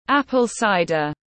Rượu táo tiếng anh gọi là apple cider, phiên âm tiếng anh đọc là /ˈæp.əl ˈsaɪ.dər/
Apple cider /ˈæp.əl ˈsaɪ.dər/